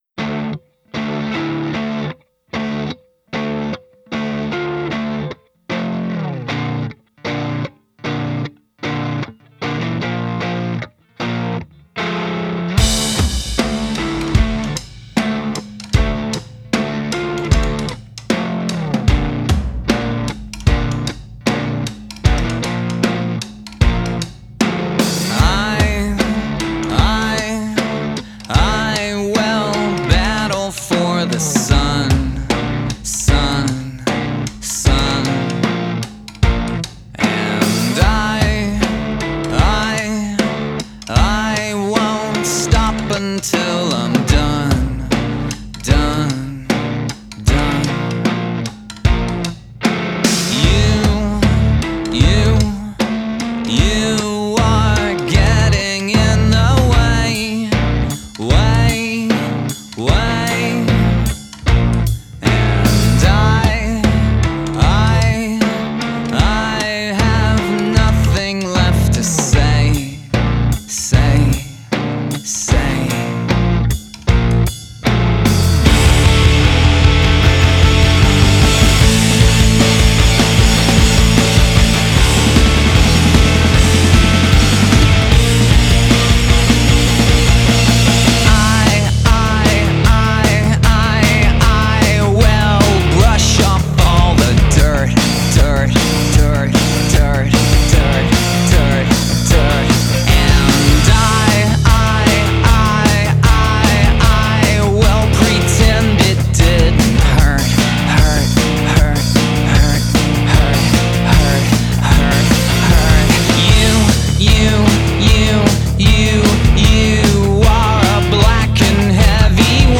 Alternative rock Indie rock Post punk